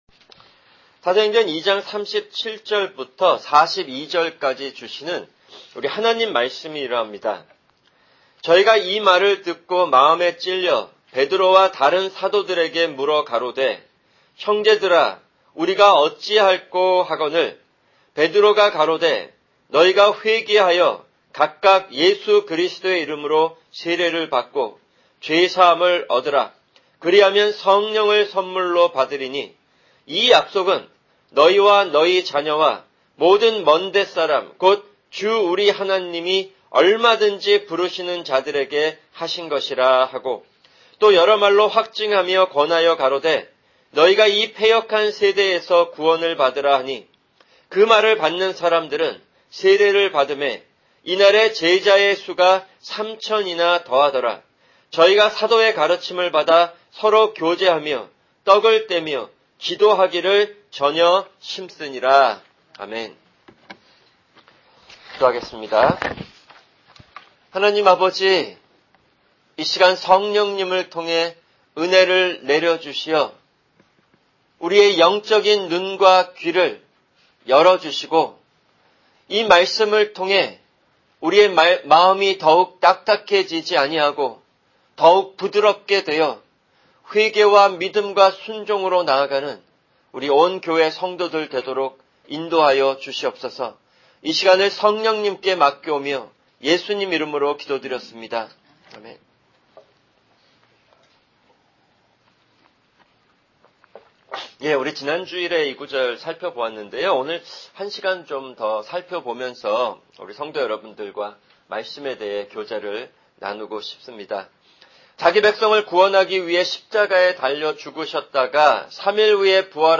[주일 설교] 사도행전 2:37-42(3)